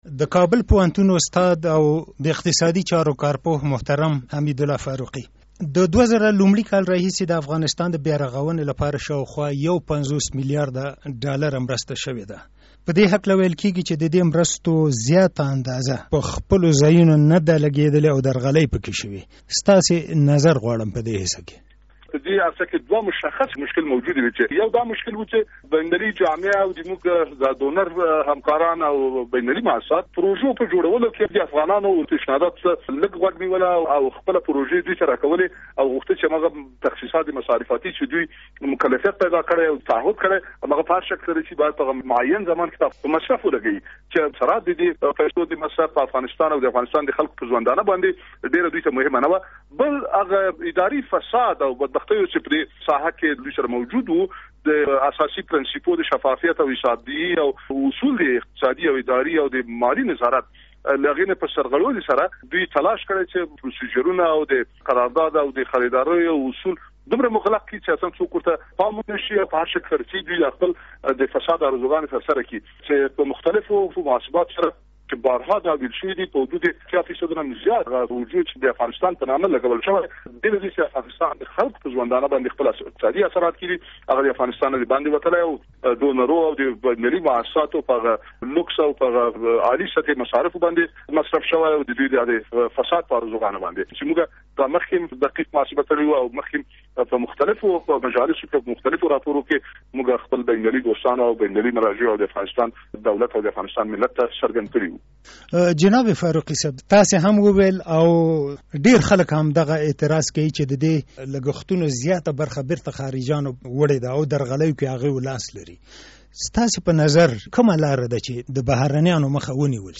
له حميدالله فاروقي سره مركه